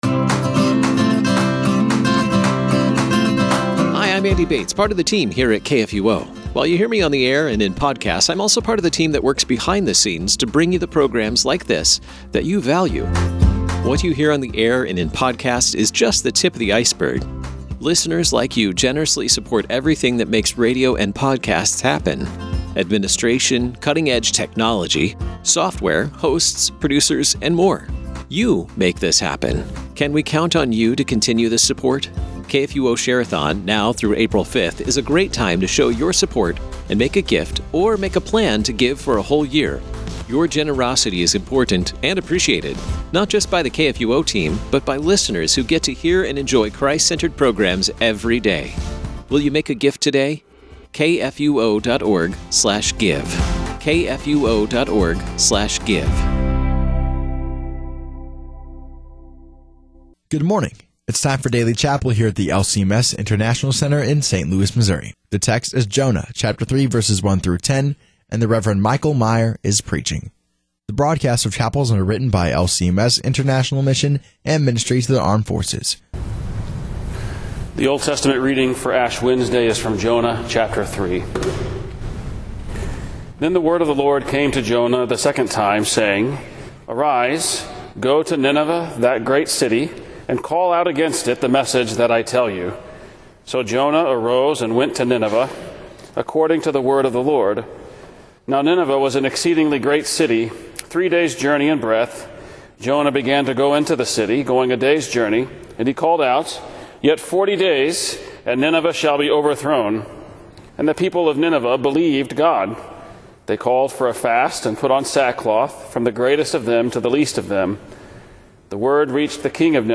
Home » Daily Chapel
>> The broadcast of chapel services is brought to you by LCMS International Mission and Ministry to Armed Forces.